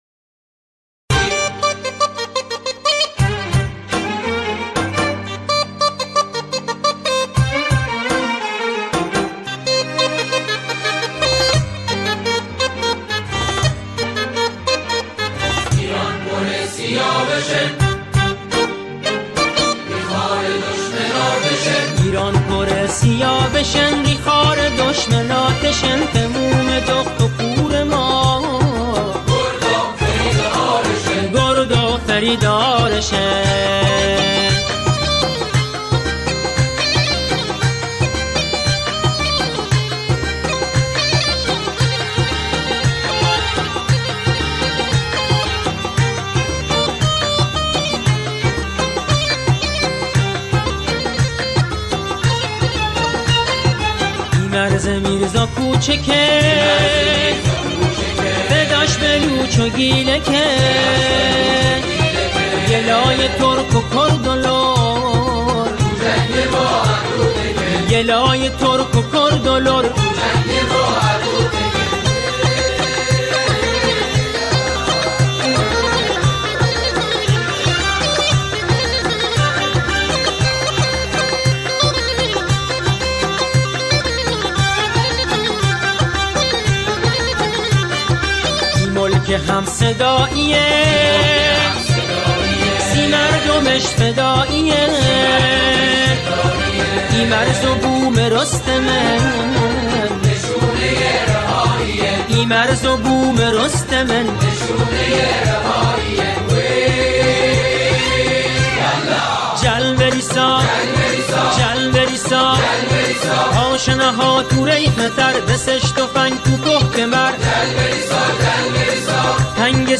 شاعر این اثر، سروده خود را، به گویش بوشهری سروده است.